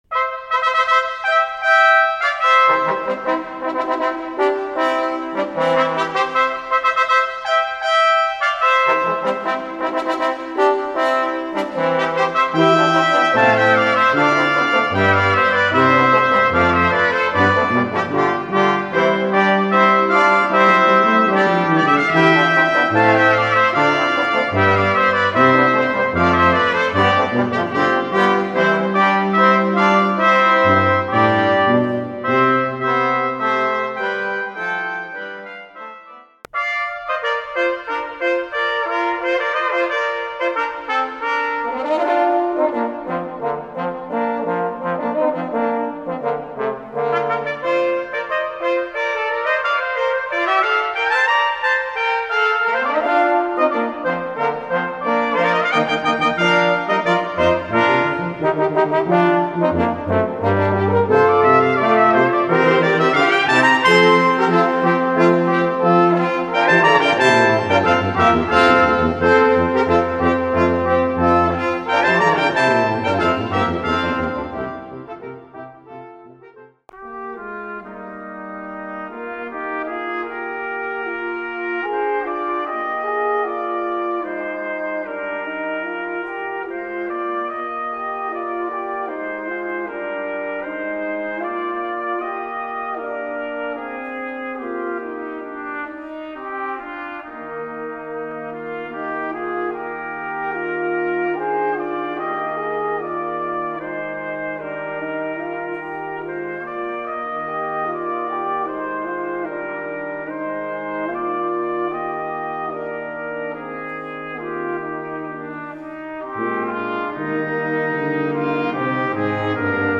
Voicing: French Horn